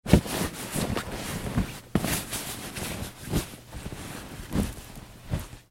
Звуки чемодана
Звук упаковки: аккуратно складываем вещи в чемодан